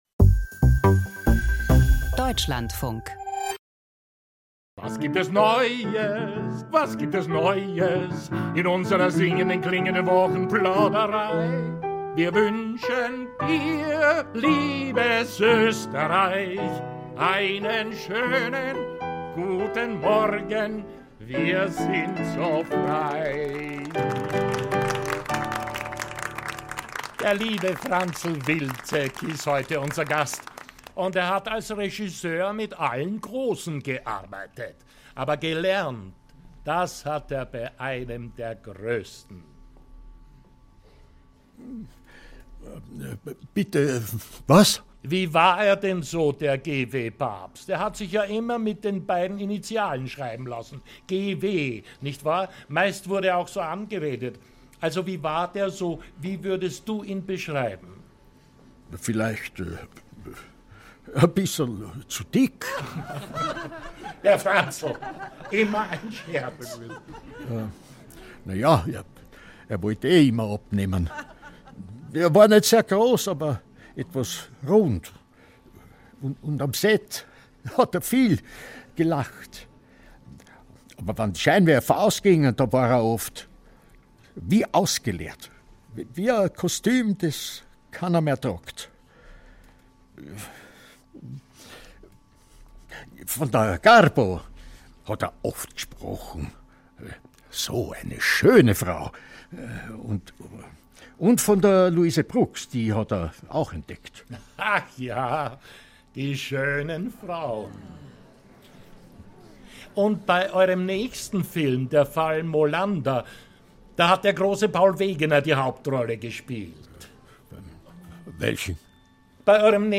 Lichtspiel (2/4) - Hörspielserie nach Daniel Kehlmann